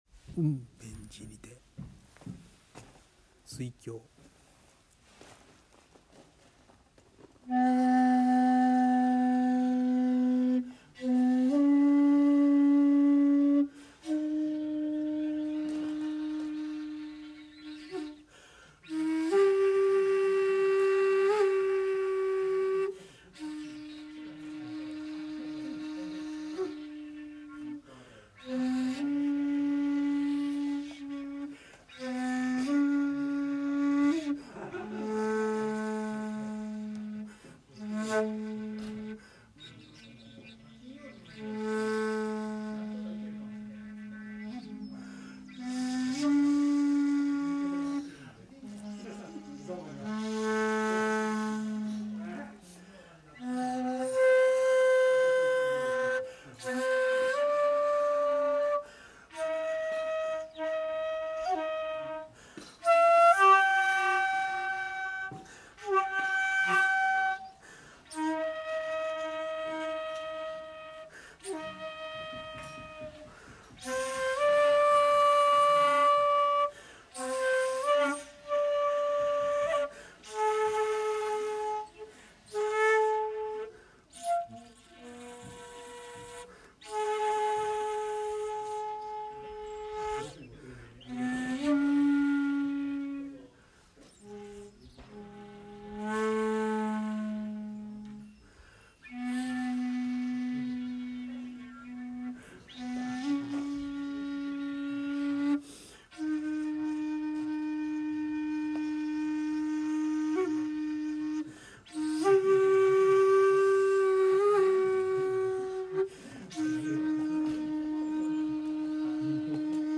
いつものように納経してから、尺八を吹奏しました。
（尺八音源：雲辺寺にて「水鏡」）